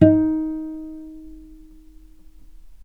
vc_pz-D#4-mf.AIF